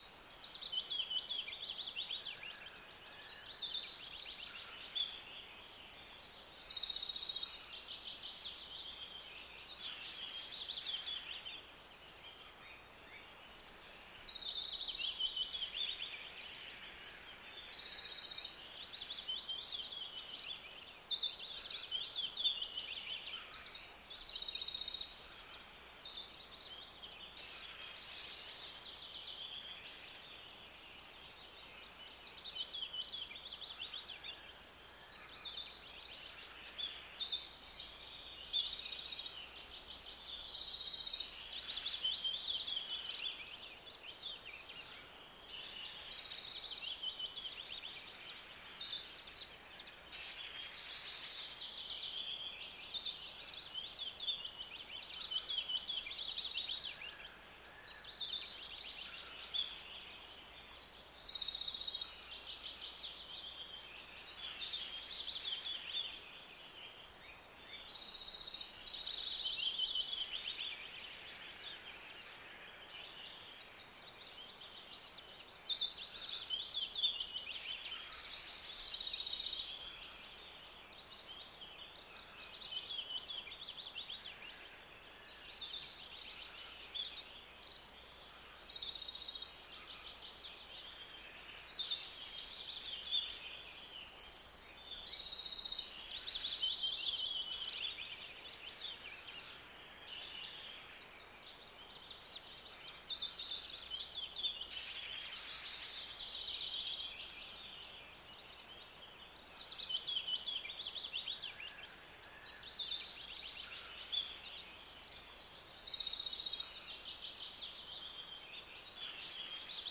bird-chirps